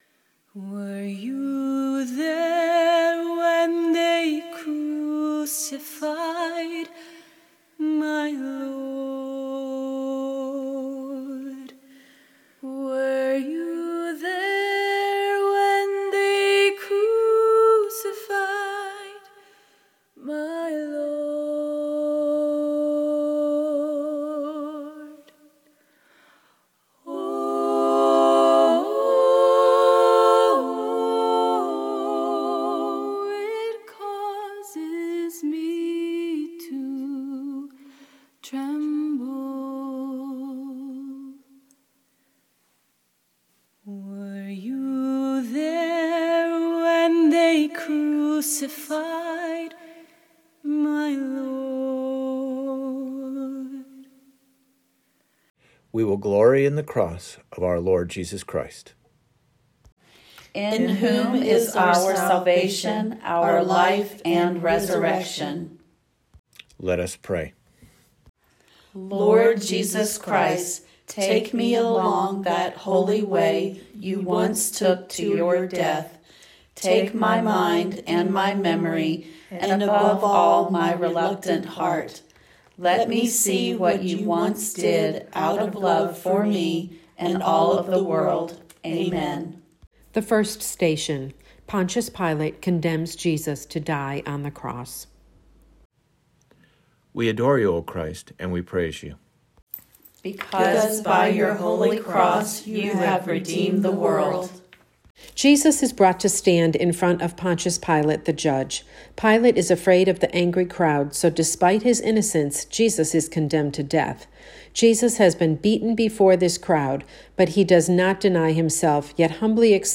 PRE-RECORDED AUDIO VERSIONWe have posted an audio only version of the Stations of the Cross for use either inside the church or at the outdoor stations, on your own schedule.